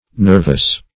nervus \nerv"us\ (n[~e]rv"[u^]s), n.